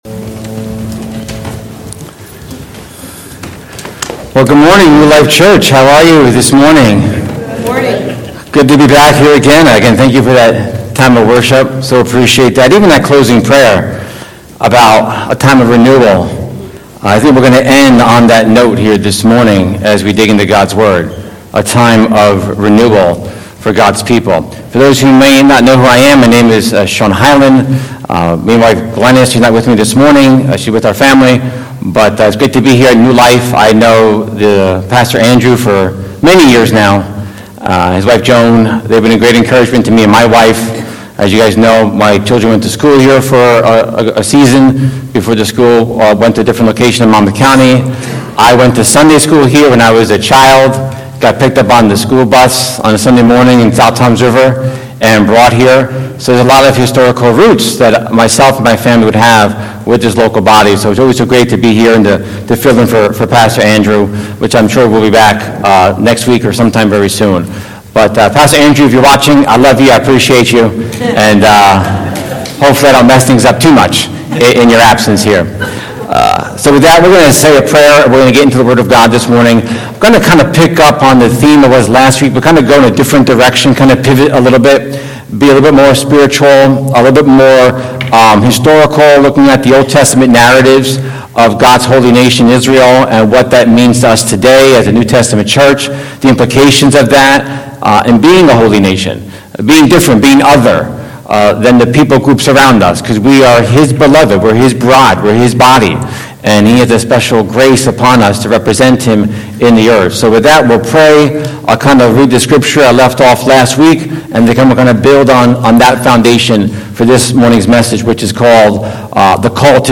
1 Sunday Service 58:36